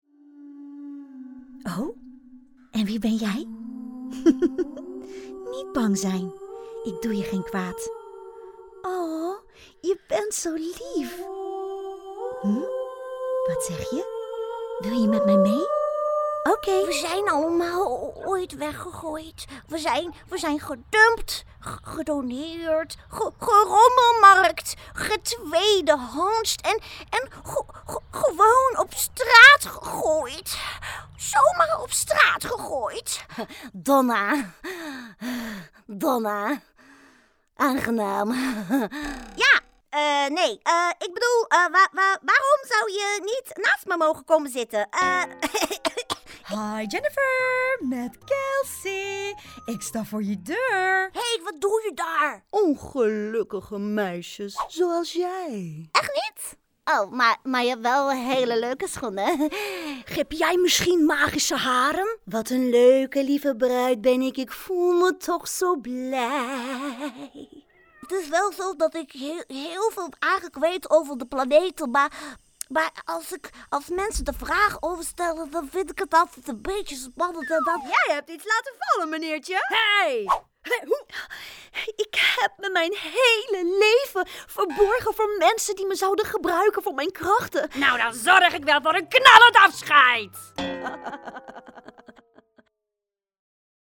Animação
Cabine tratada
Microfone Rode NT1a
Mezzo-soprano